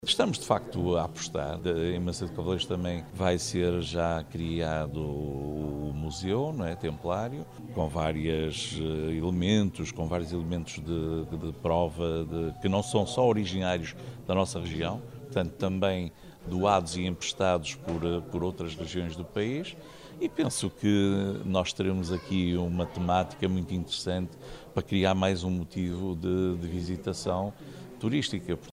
O presidente da Câmara de Macedo, Benjamim Rodrigues, considera que este é um tema que pode ser uma atração para os turistas: